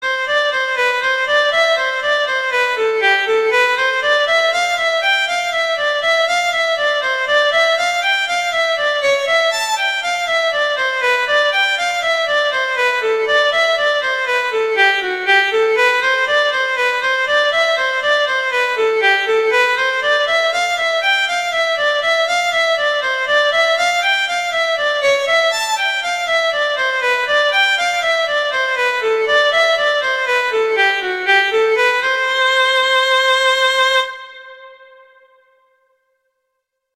arrangements for violin solo
classical, children